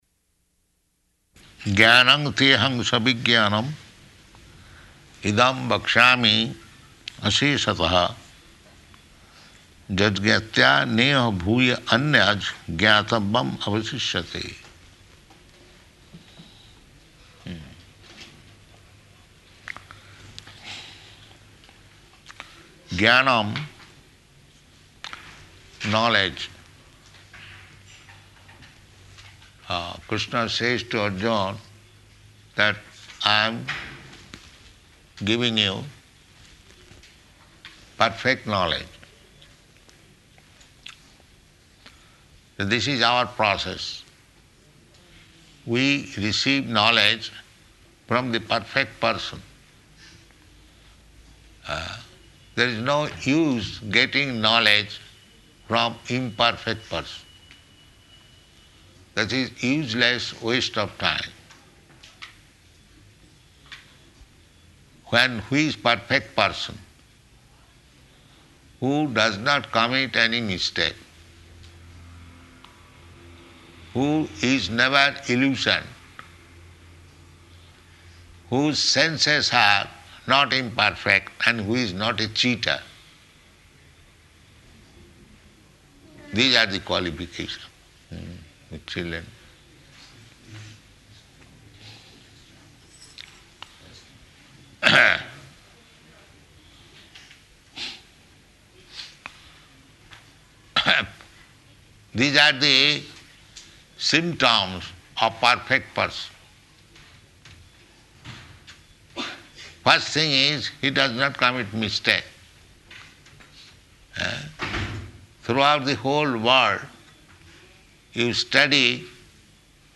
Location: Nairobi
[children making noise] [aside:] The children...